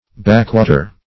Backwater \Back"wa`ter\, n. [Back, a. or adv. + -water. ]